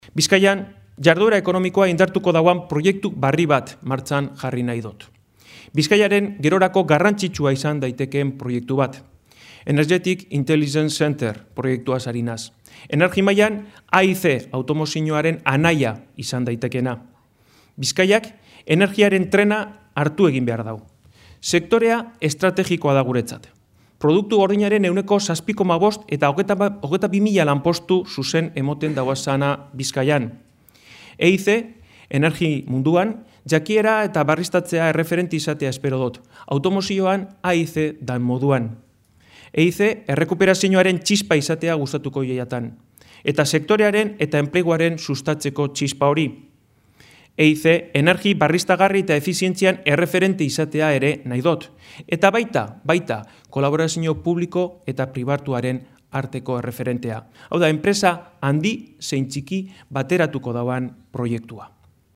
Unai Rementeria, Bizkaiko ahaldun nagusirako EAJ-PNVren hautagia, eta Juan Mari Aburto, Bilboko Alkatetzarako hautagai jeltzalea, Bilboko Meliá hotelean elkartu dira gaur goizean, herrialdeko eta hiriburuko enpresa eta ekonomia sektoreko hogei bat eragile eta ordezkarien aurrean ekonomia suspertzeko eta enplegua sortzen laguntzeko planteatuko dituzten proposamenak aurkezteko.